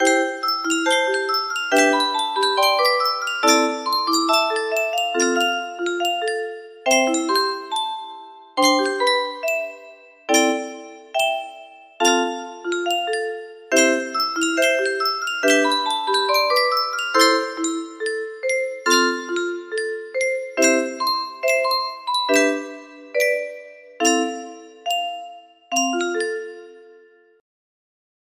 music box melody
Grand Illusions 30 (F scale)